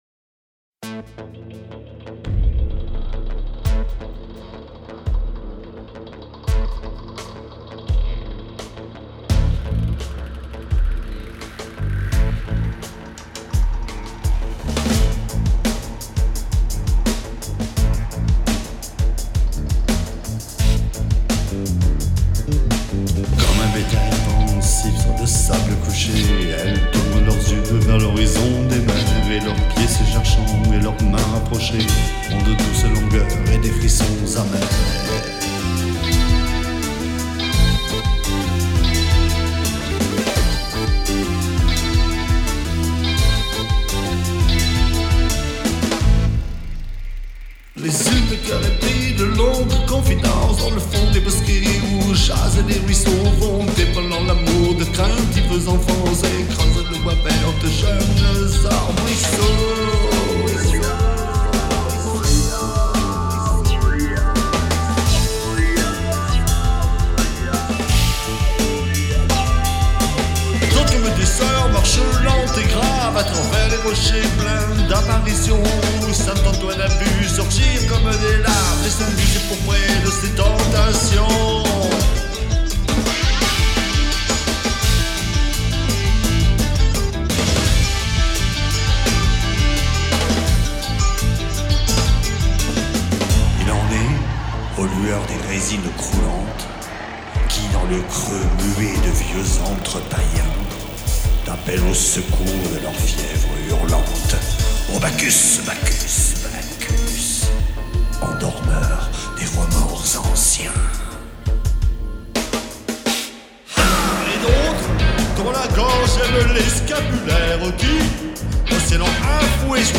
Femmes Damnées de Charles Baudelaire, Musiqué, Rocké et chanté.